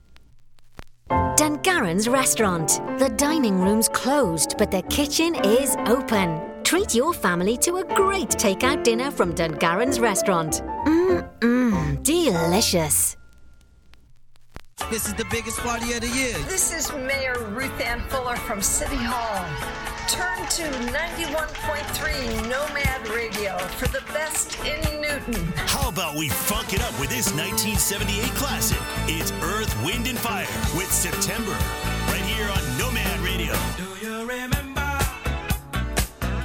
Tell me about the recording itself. One of the most fun details of the night was Nomad Radio 91.3. The whole show was broadcast via FM radio to everyone’s car radios.